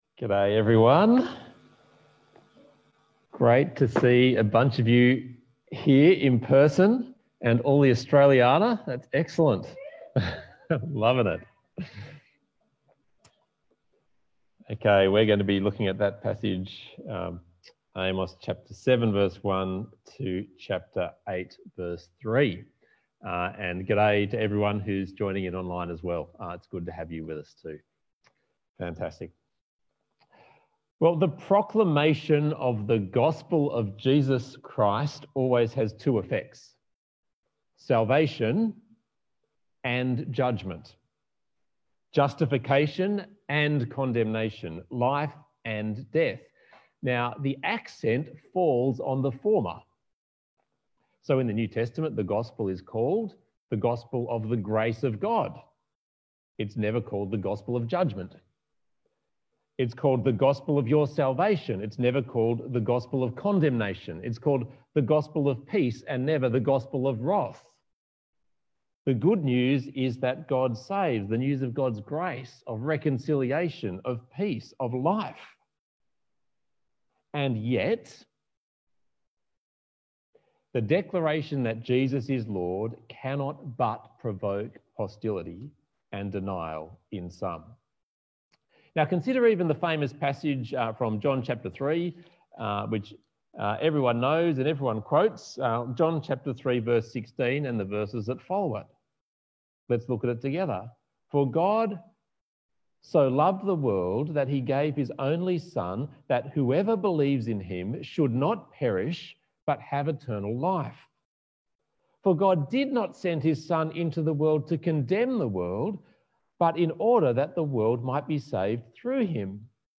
Bible Talk